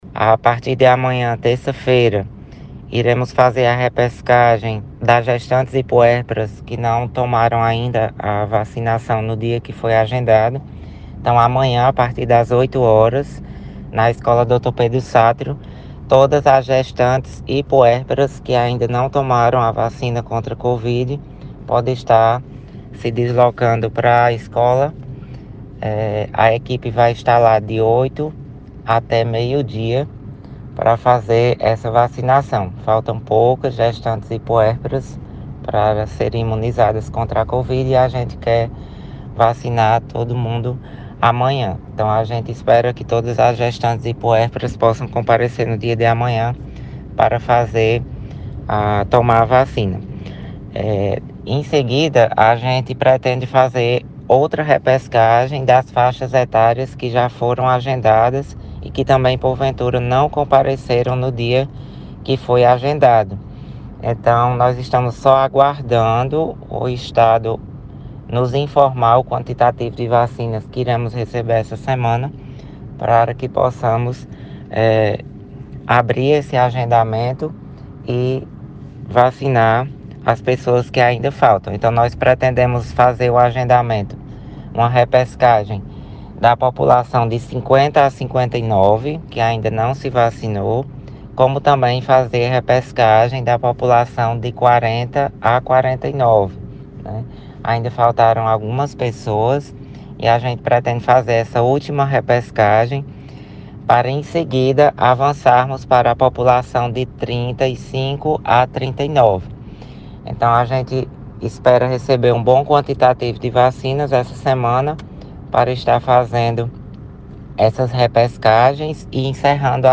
O objetivo da ação é imunizar todas as pessoas que estão nesta situação. A informação é do Secretário Ivo Leal, à FM Cultura, na manhã desta segunda-feira, 12.